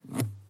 Механическое скроллирование